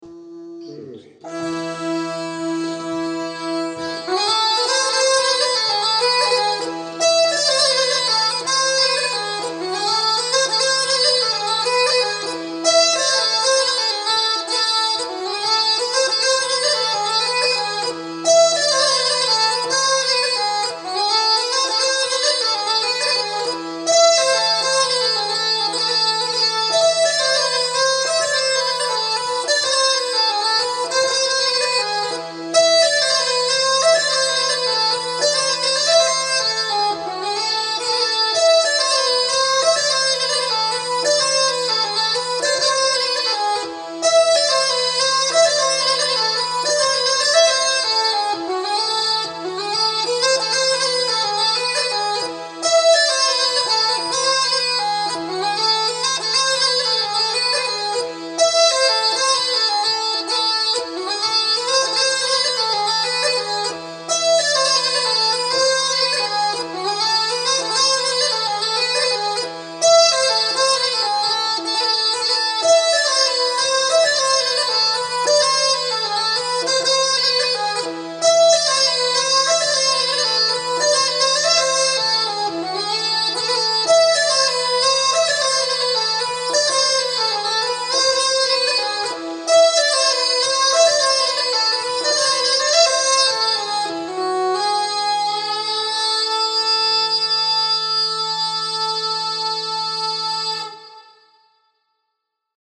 Jag spelade o pratade om den svenska säckpipan samt om min hardingfelan.
Började med min svenska säckpipa där jag spelade en polska efter Lomians-Guten
Polska-efter-Lomians-Guten.mp3